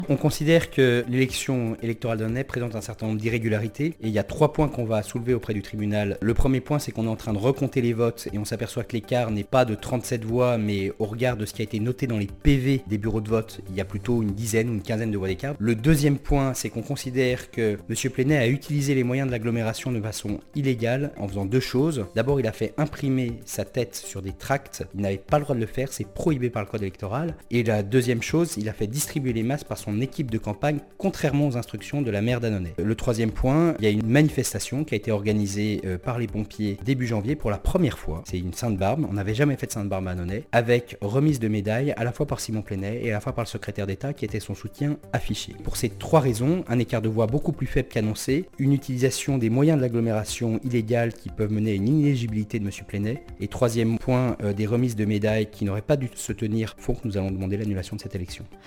Aujourd’hui, au micro de Chérie FM le candidat a détaillé les arguments juridiques sur lesquels il compte s’appuyer pour déposer ce recours.